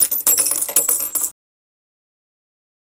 フリー効果音：コイン2
フリー効果音｜ジャンル：かんきょう、コインの音2つ目！コインの連投シーンなどにぴったり！
coin2.mp3